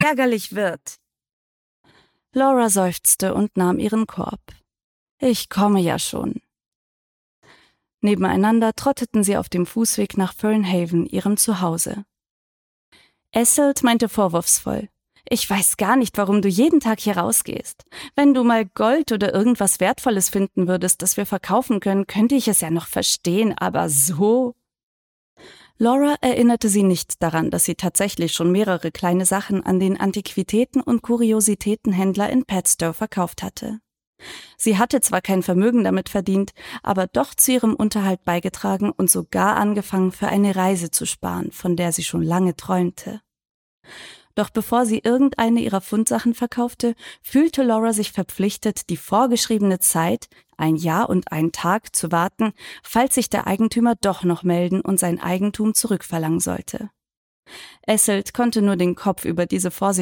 Gestrandet in Cornwall - Hörbuch